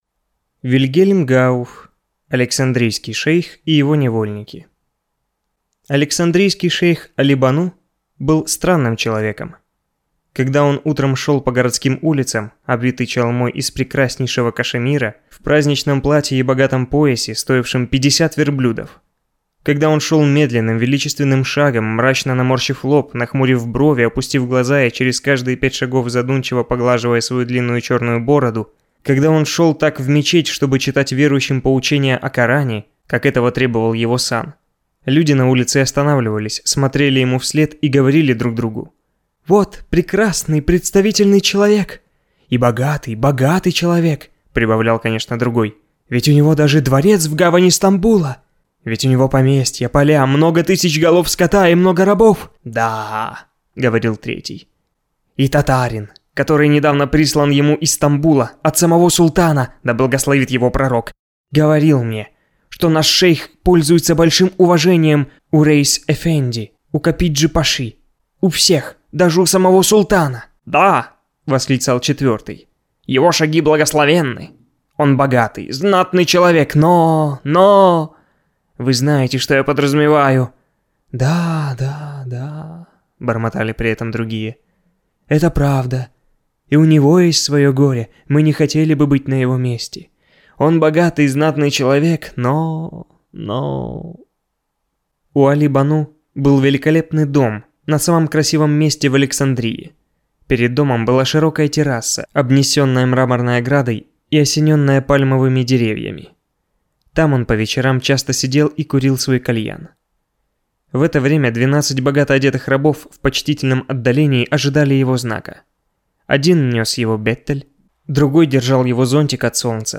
Aудиокнига Александрийский шейх и его невольники